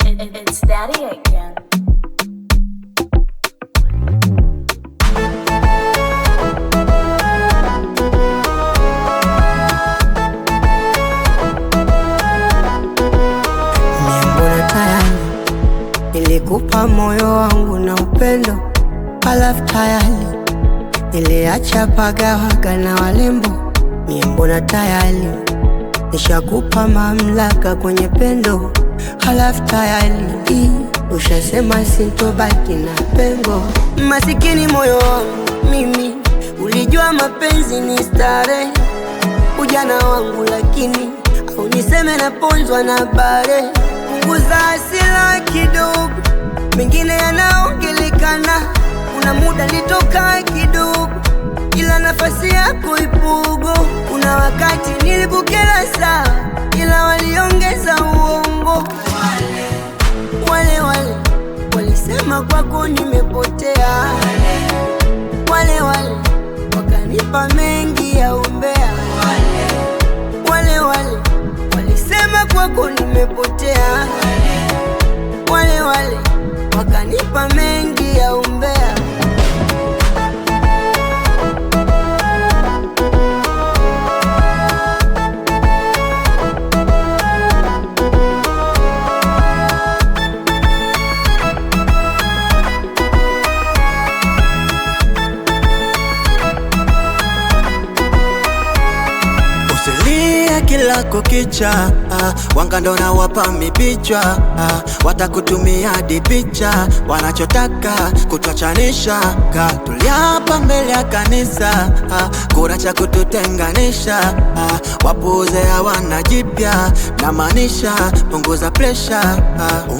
upbeat Afro-Beat/Bongo Flava single
smooth vocals and uplifting lyrics